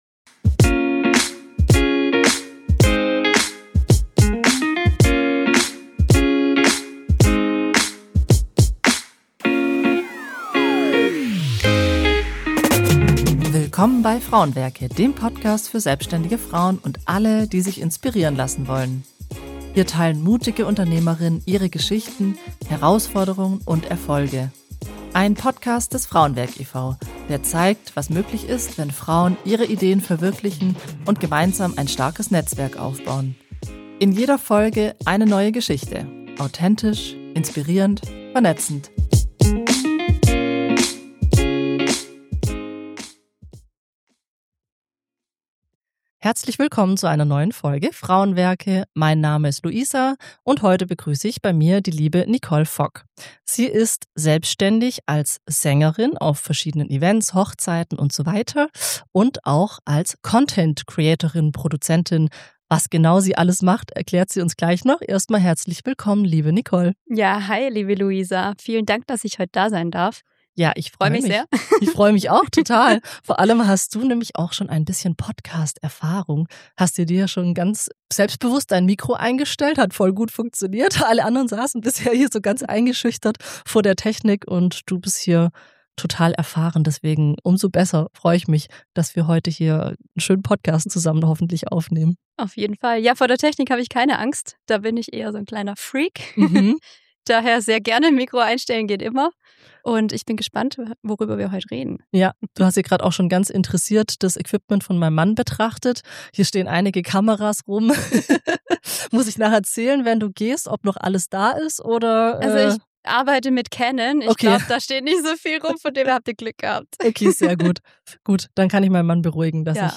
Außerdem erklärt sie, wie aus ihrer Leidenschaft für Kamera und Content-Marketing während Corona ein zweites Standbein entstanden ist – und warum sie jetzt endlich auch Zeit für sich selbst und ihren eigenen Content einplanen möchte. Ein lebendiges, ehrliches Gespräch über Flexibilität, Mehrfachtalent und den Mut, einfach mal anzufangen – mit allem, was dazugehört.